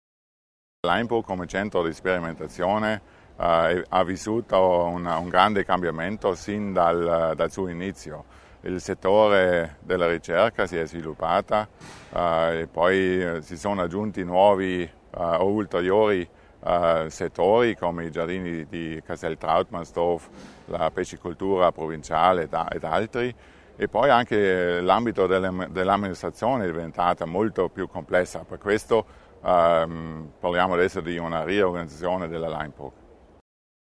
L'Assessore Schuler spiega le novità del Centro di Laimburg